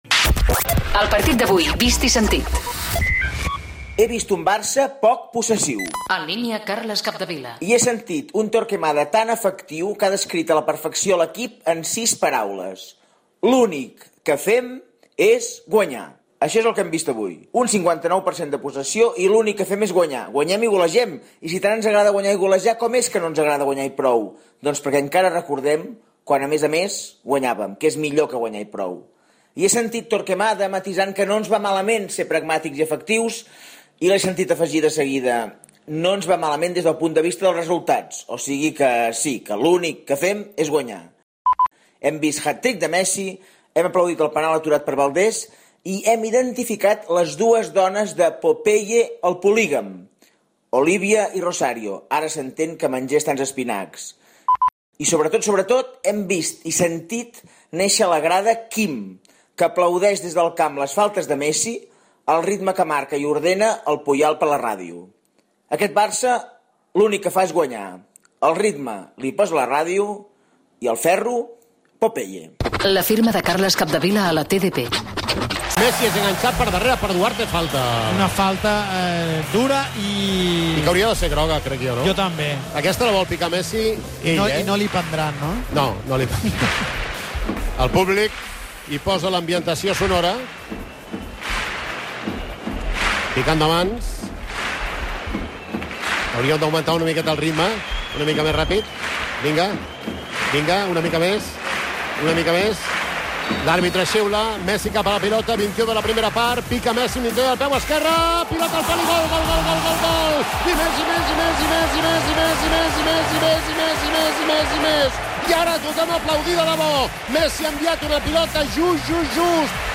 Narració dels tres gols de Leo Messi i un de Gerard Piqué al partit contra l'Ajax
Esportiu